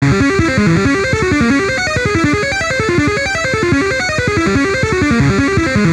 Four Finger Warm Up.wav